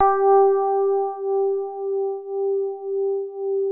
TINE HARD G3.wav